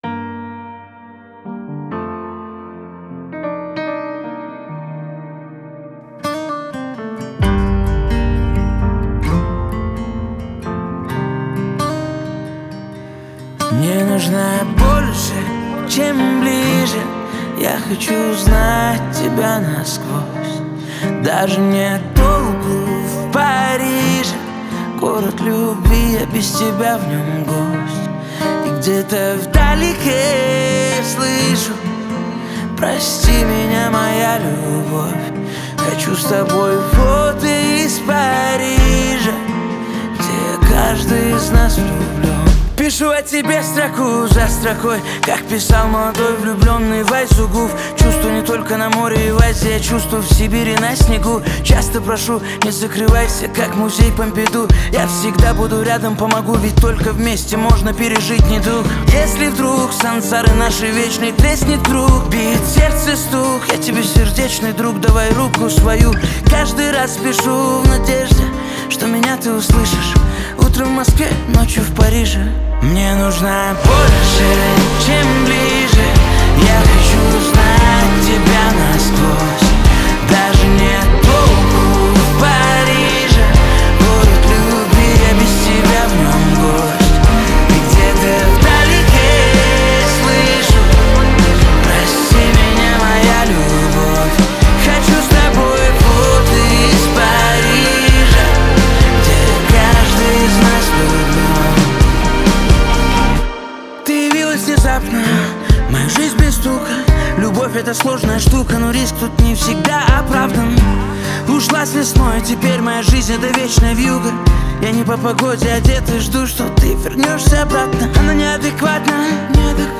Категория Русская музыка